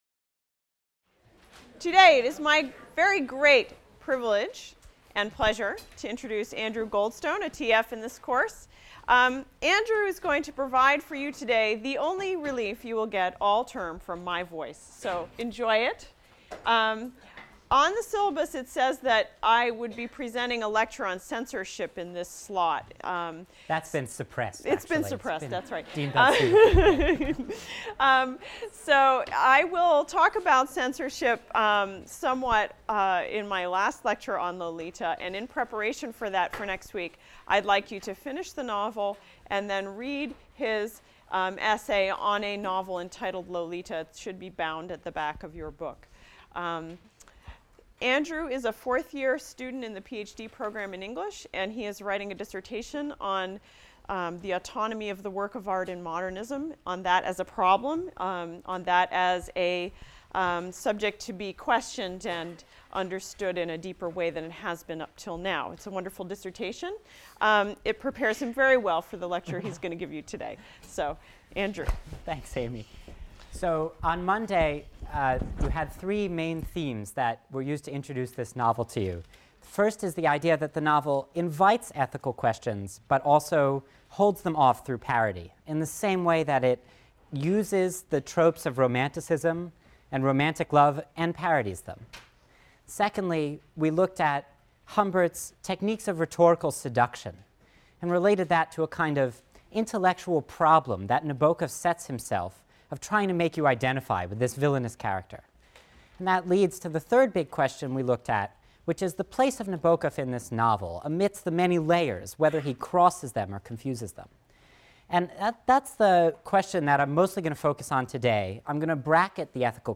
Guest Lecture